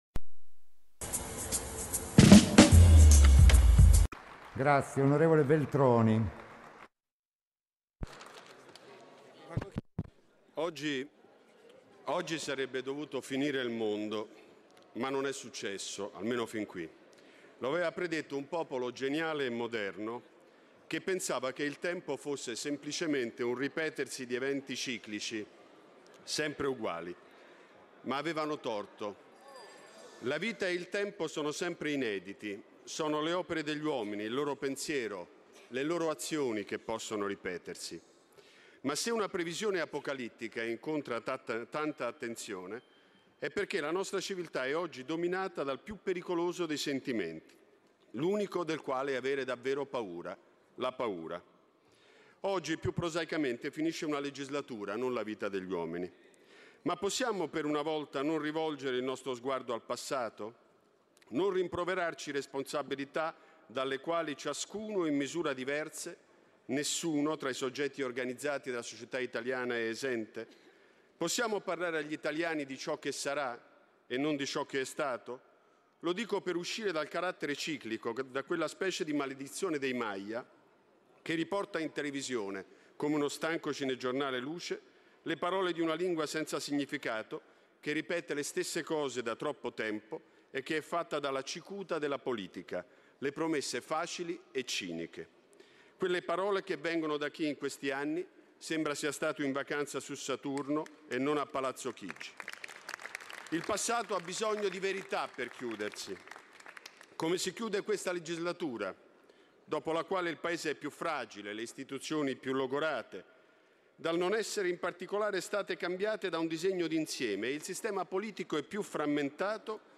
walter-veltroni-ultimo-discorso-camera.mp3